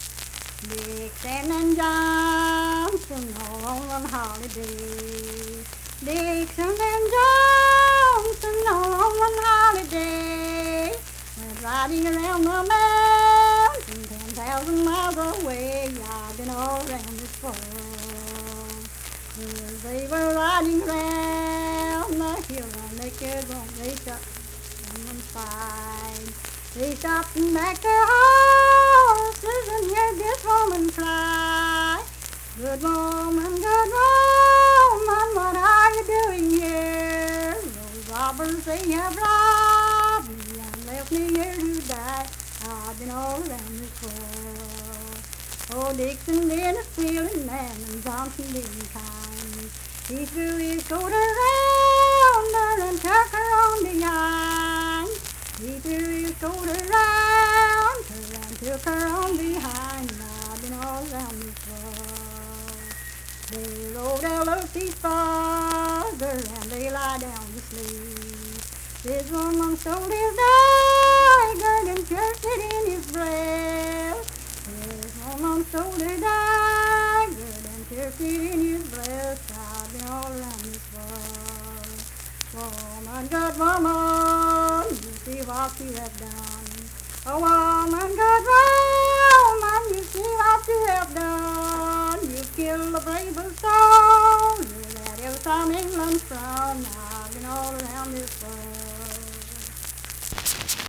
Unaccompanied vocal music performance
Verse-refrain 5(4-5w/R).
Voice (sung)
Mingo County (W. Va.), Kirk (W. Va.)